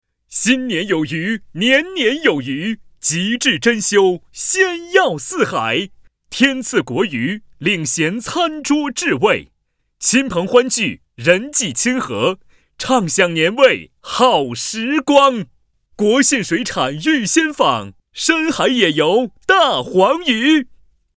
A男45号
【喜庆电视广告】国信水产
【喜庆电视广告】国信水产.mp3